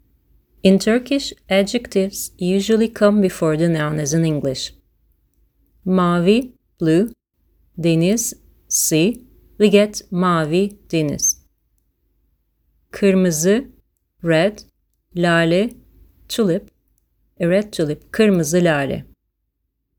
To help avoid confusion, I have italicised the Irish words and phrases, and I’ve also included some Turkish audio clips so you can hear what the language sounds like!
Turkish Adjectives
Turkish-Adjectives.mp3